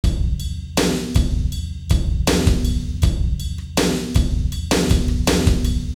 batterie_clean_3.flac